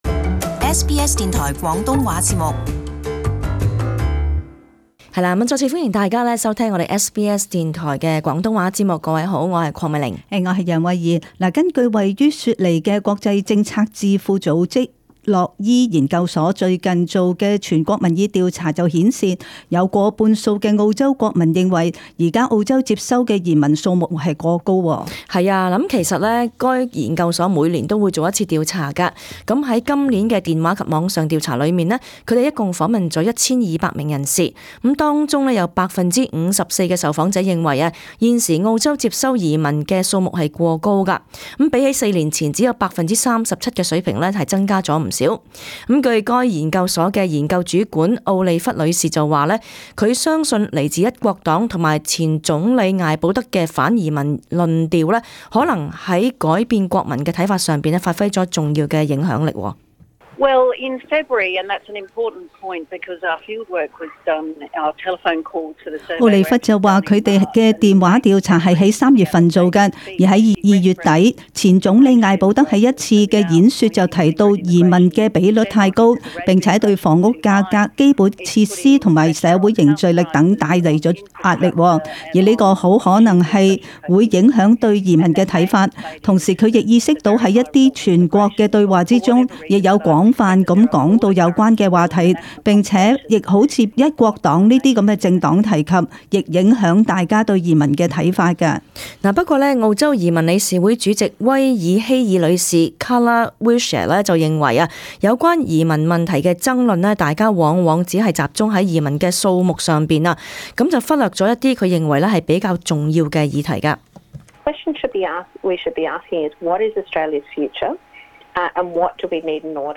【時事報導】大部分澳洲人認為接收移民過多